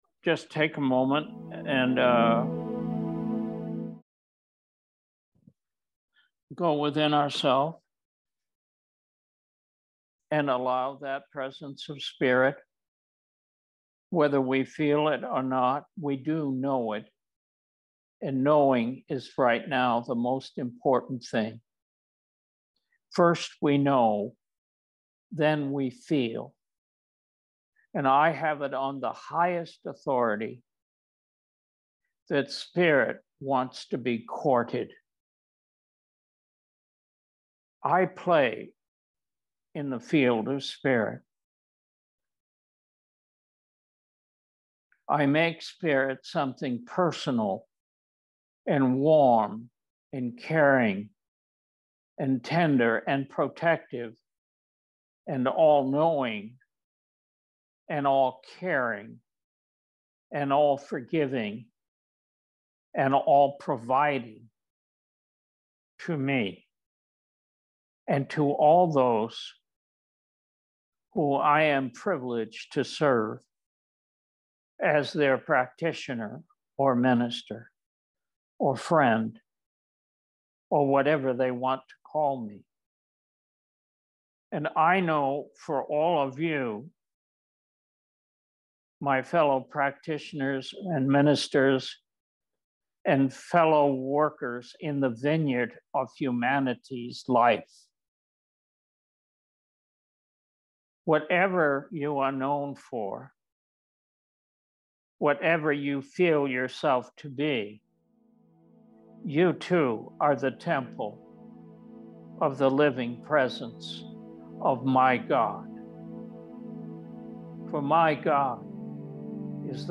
For those of you who missed this powerful talk - Stay tuned: